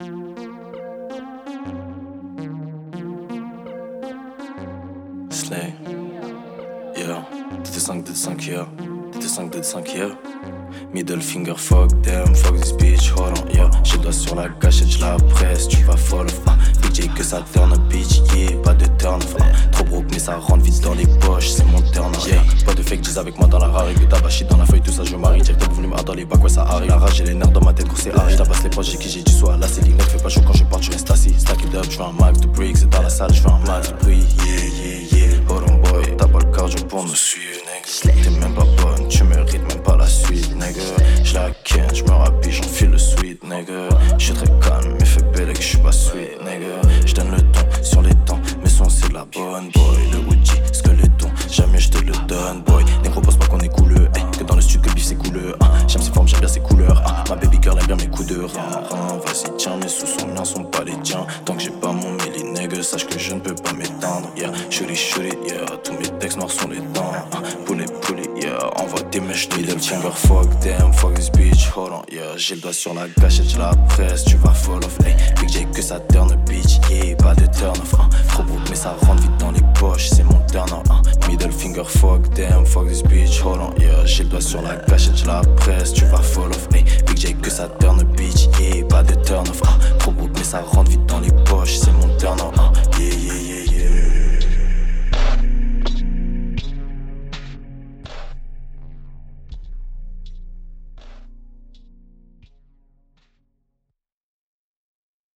rappeur
Un morceau au rythme endiablé